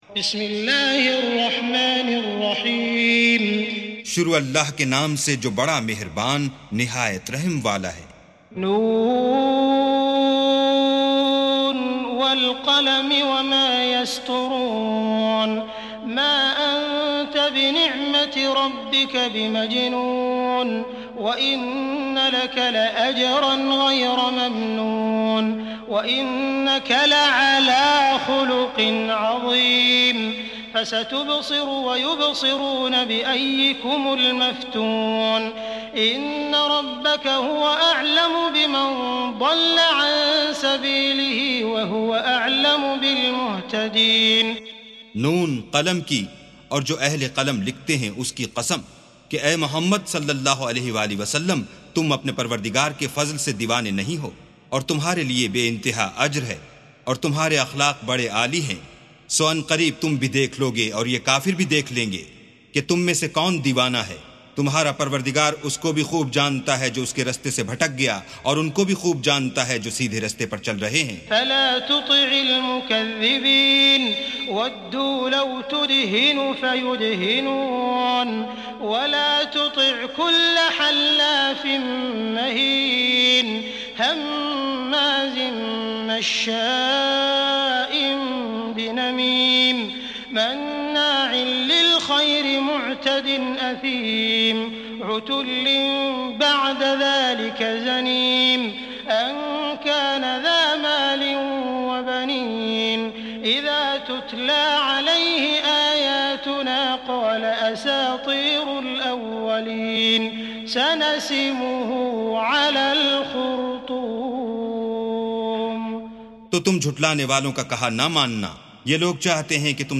سُورَةُ القَلَمِ بصوت الشيخ السديس والشريم مترجم إلى الاردو